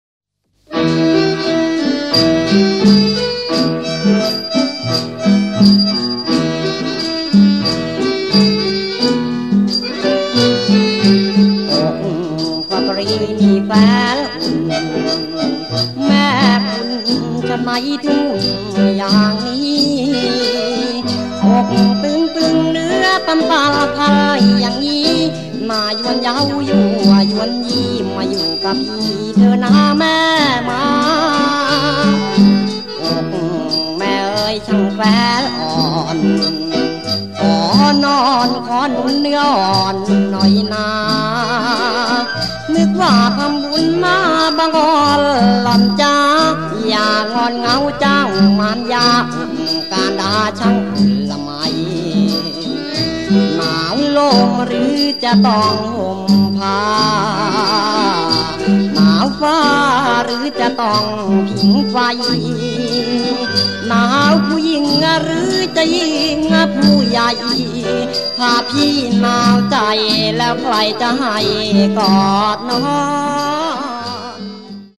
สินค้า / เพลงลูกทุ่ง ยอดฮิต ไพเราะตลอดกาล